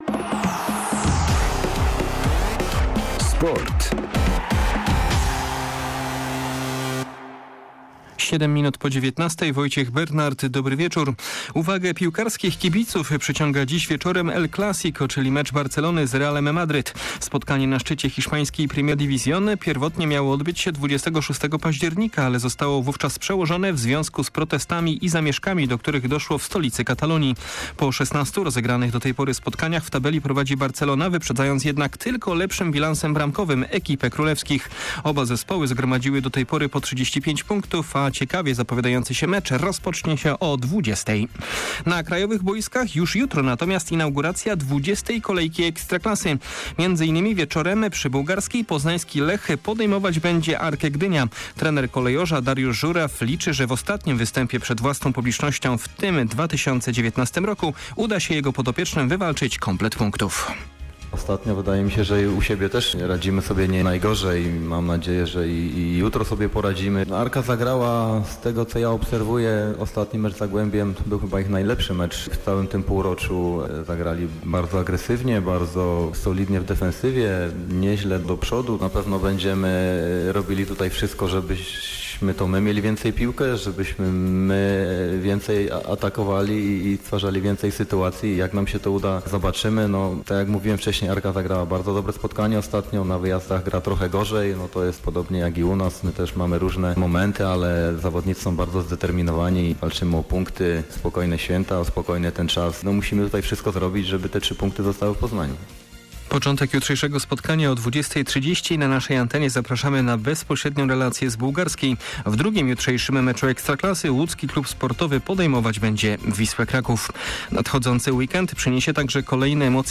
18.12. SERWIS SPORTOWY GODZ. 19:05